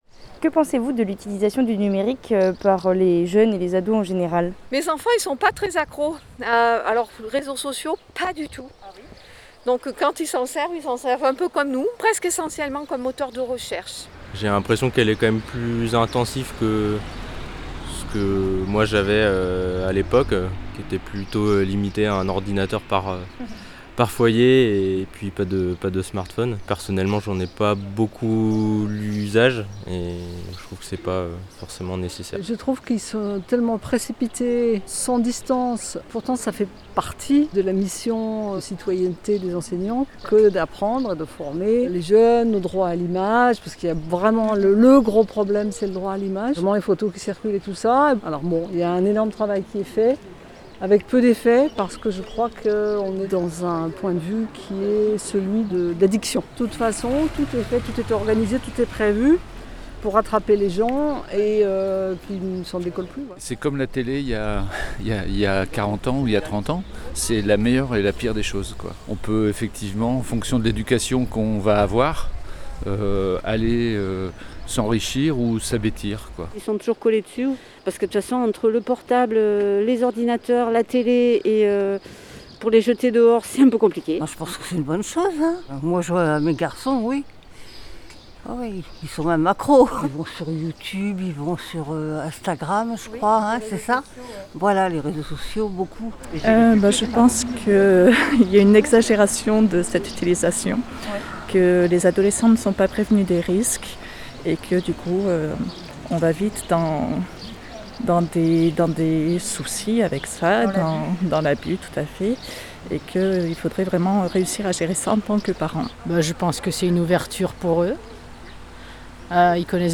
Un micro-trottoir réalisé sur le marché de Dieulefit auprès d’adultes et de parents, autour d’une question : Que pensez-vous de l’utilisation du numérique par vos enfants et les ados en général ?
Micro-trottoir-2.mp3